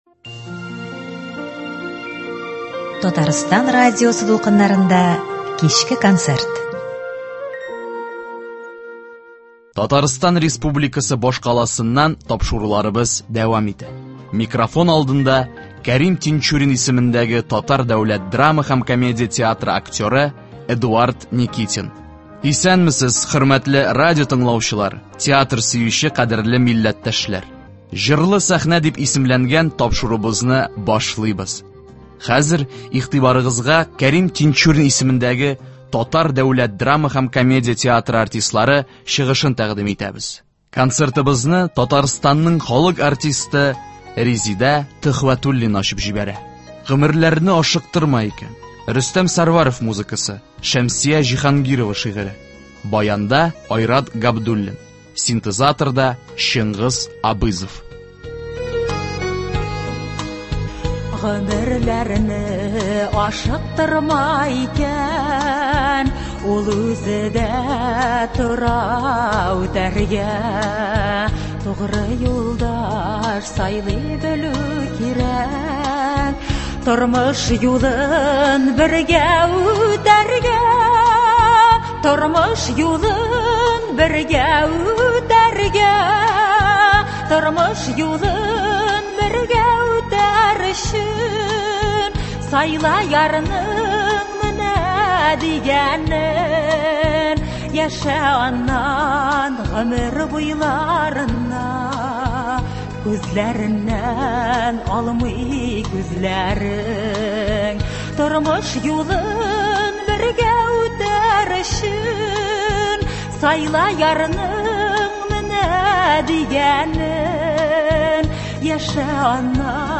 Драма артистлары җырлый.